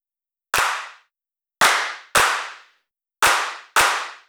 Track 15 - Hand Claps 01.wav